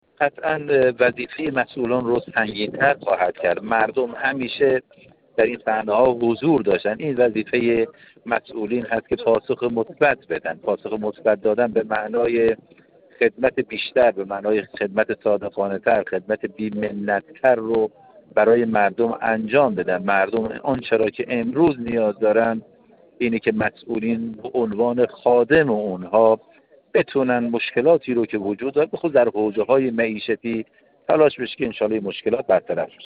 بهنام سعیدی، دبیر دوم کمیسیون امنیت ملی و سیاست خارجی در گفت‌وگو با ایکنا درباره پیام حضور باشکوه مردم در یوم‌الله ۲۲ بهمن و جشن چهل و شش سالگی انقلاب اسلامی در سطح ملی و بین‌المللی، گفت: مردم ایران اسلامی در طول 45 سال از پیروزی انقلاب شکوهمند اسلامی که در سال 57 رقم خورد، همیشه در تمام صحنه‌های انقلاب حضور فعال و گسترده داشتند و همیشه گوش به فرمان مقام معظم رهبری بودند.